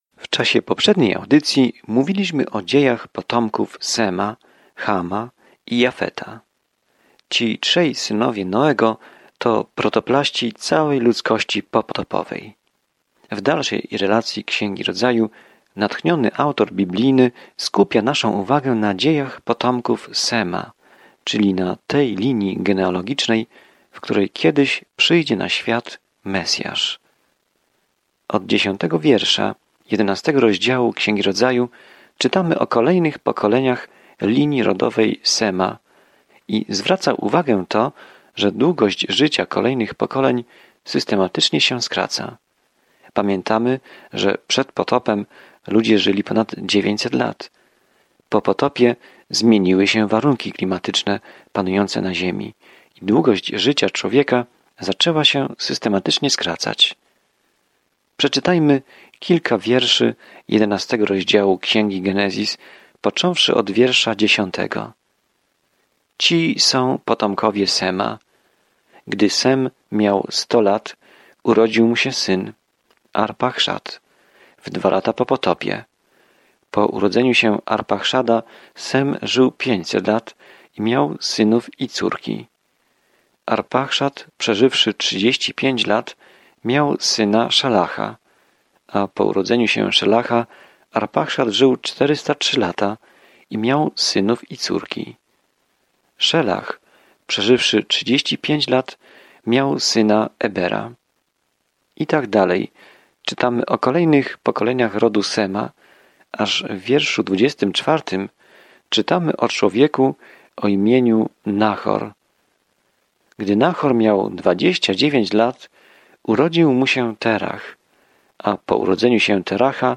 Pismo Święte Rodzaju 11:5-32 Dzień 14 Rozpocznij ten plan Dzień 16 O tym planie Tutaj wszystko się zaczyna – wszechświat, słońce i księżyc, ludzie, relacje, grzech – wszystko. Codziennie podróżuj przez Księgę Rodzaju, słuchając studium audio i czytając wybrane wersety słowa Bożego.